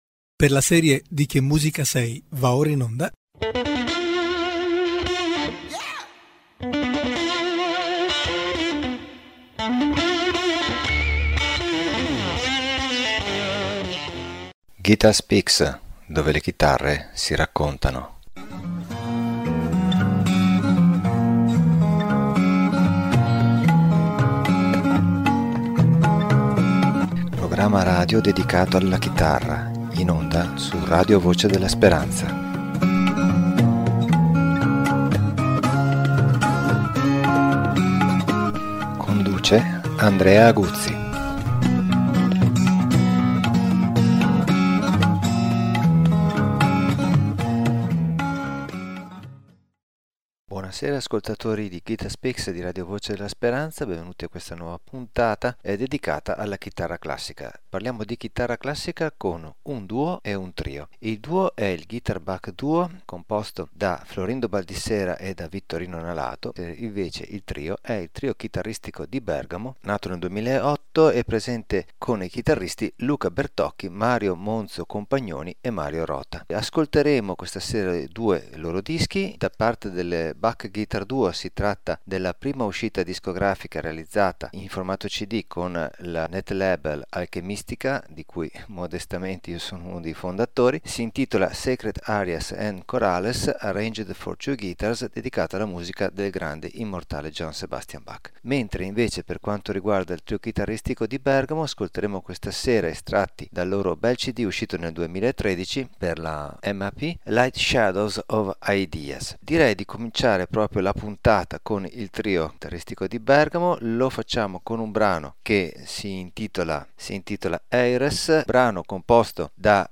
chitarra classica
musica per chitarra contemporanea